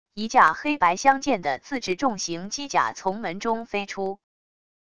一架黑白相间的自制重型机甲从门中飞出wav音频